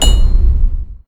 armorhit.ogg